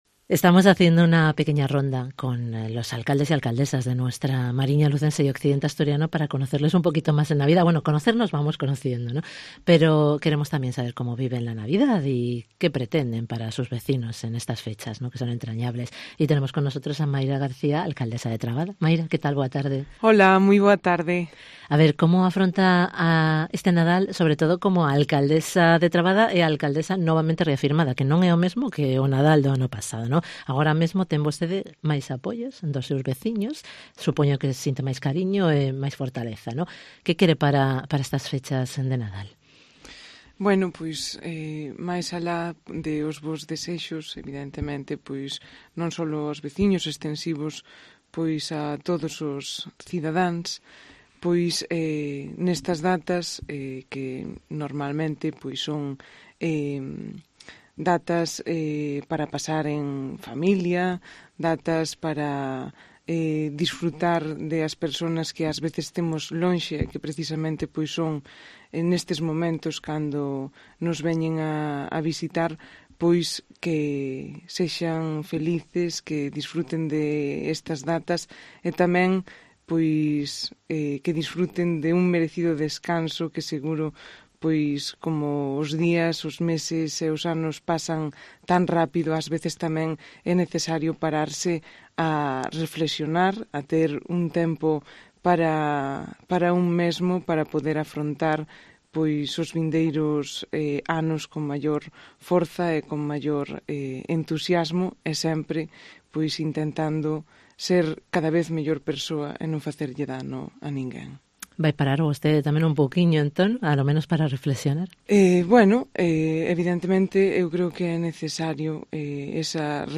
ENTREVISTA con Mayra García, alcaldesa de Trabada